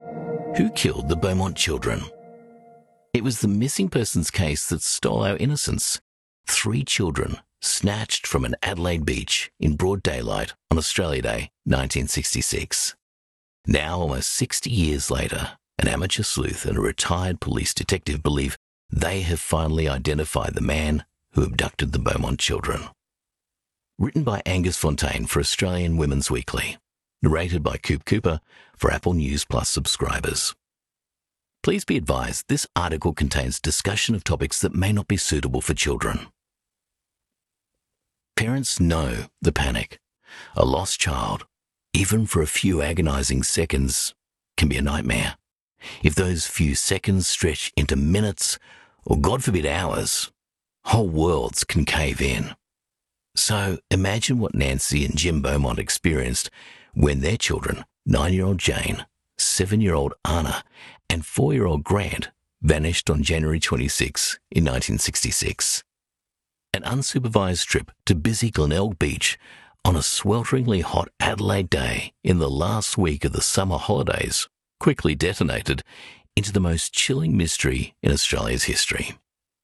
Dynamic, approachable, friendly and natural Australian VoiceOver
Narration
True Crime Narration